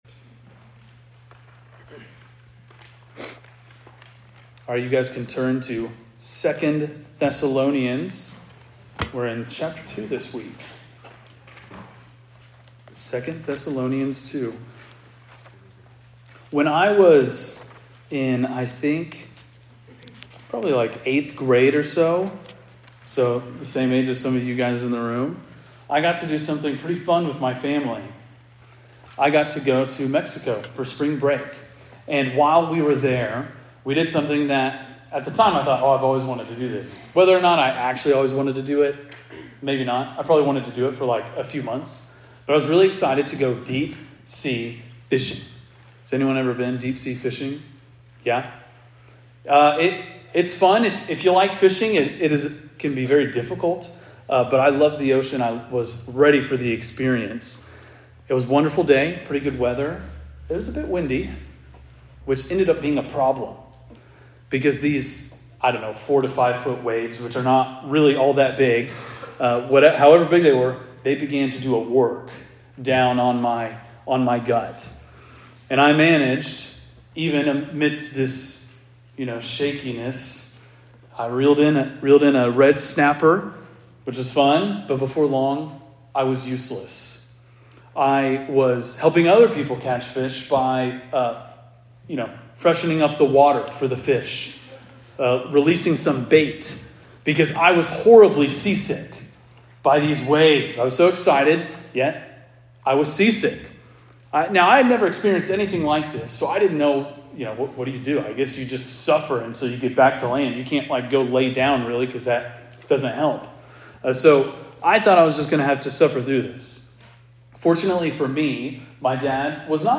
preaches through 2 Thessalonians 2.&nbsp